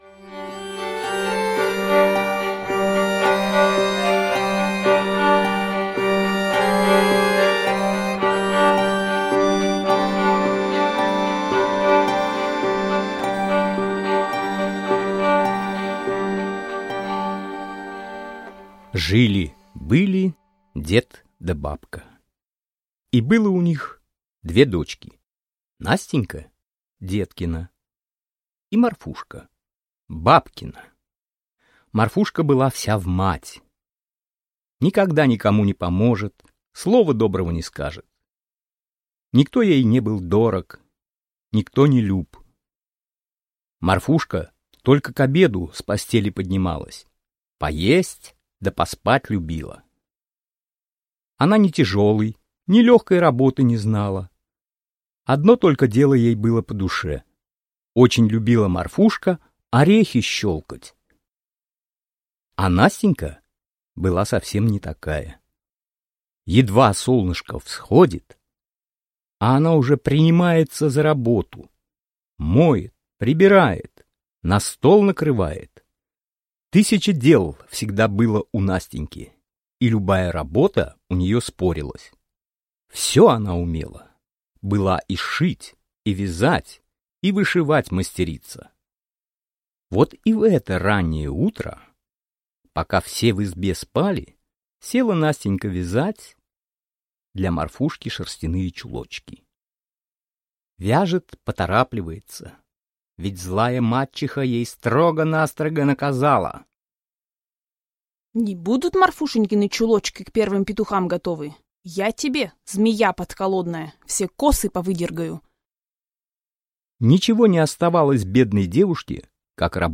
Audio kniha